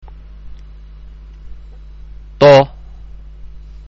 ഉച്ചാരണം (പ്ലേ ബട്ടണ്‍ അമര്‍ത്തുക)